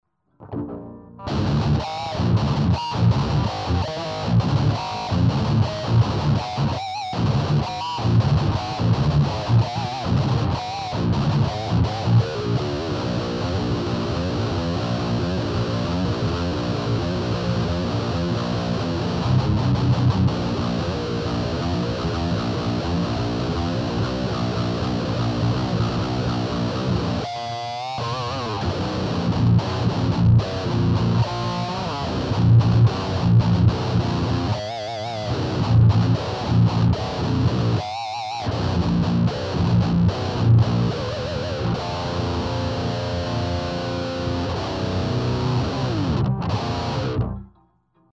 примеров звука именно этого типа перегруза ("Metall") не меняя ручек настроек, а меняя лишь типы кабинетов.
(Гитара Ibanez RG-270, строй Drop C , струны 13-68).